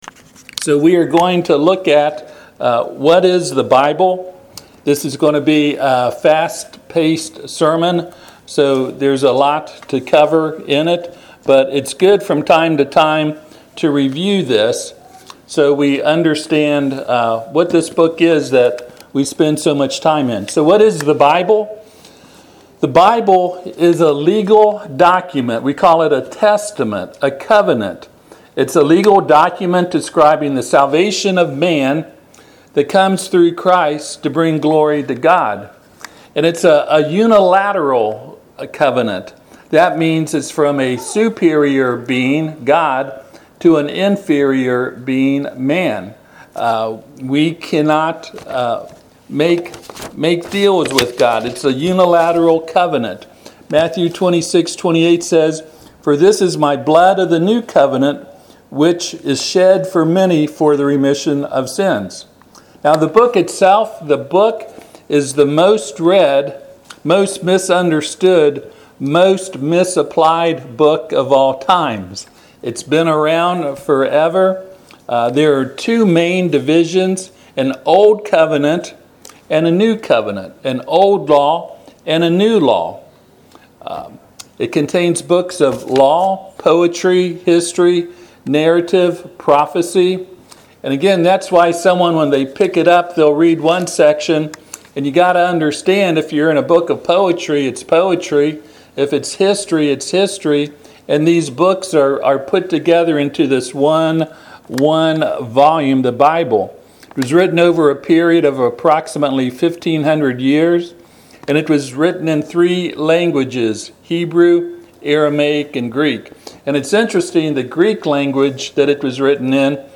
Passage: 2Peter 1:20-21 Service Type: Sunday AM Topics